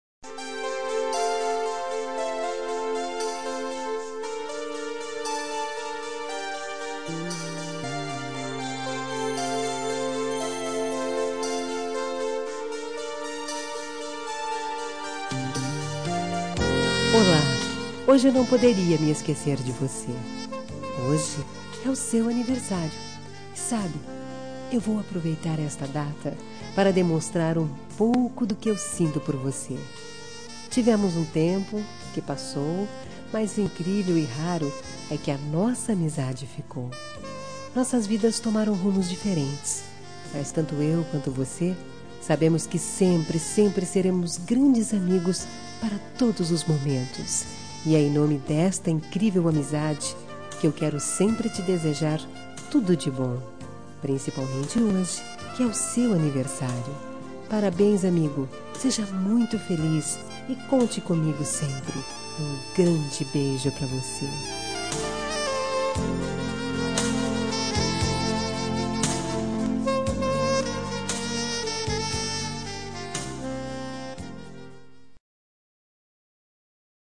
Telemensagem de Aniversário de Ex. – Voz Feminina – Cód: 1381